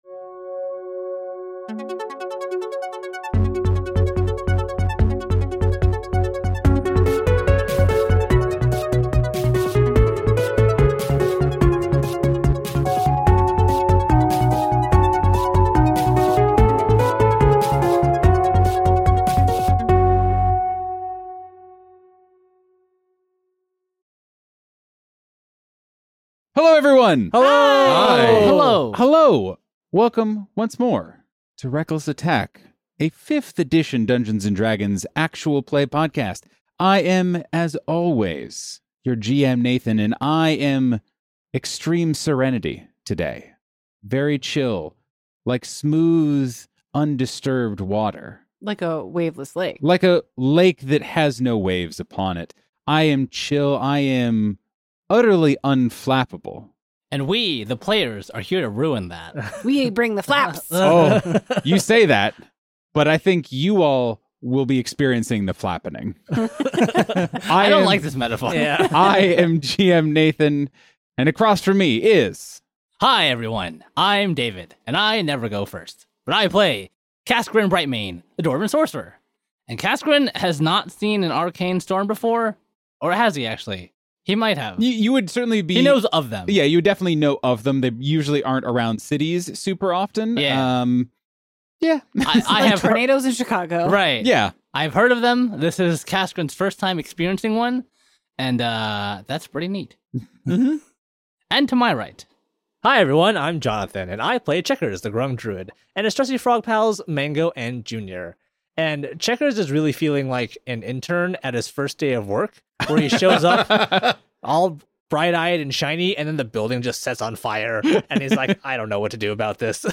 In this series, the RA Crew plays through Emberwind, a game that was recommended by one of our patrons. Emberwind is a fully modular, GM-optional tabletop RPG where stories can run themselves or be guided by a storyteller.